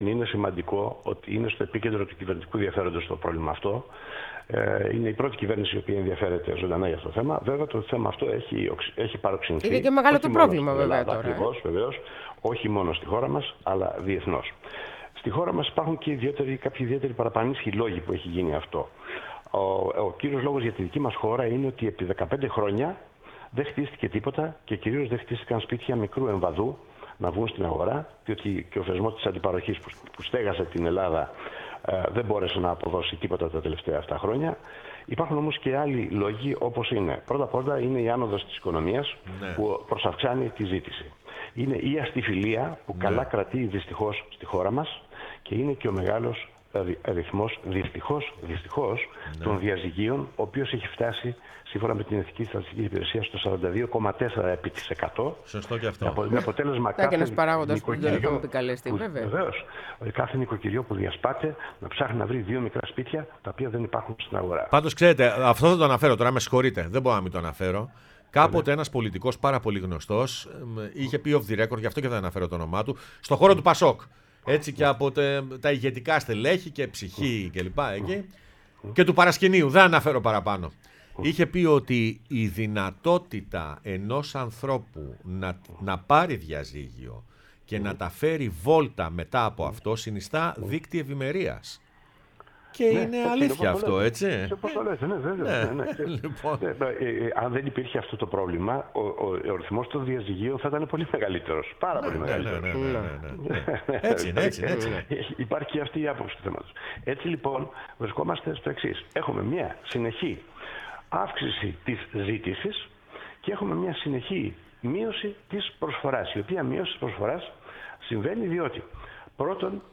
μίλησε στην εκπομπή “Πρωινές Διαδρομές”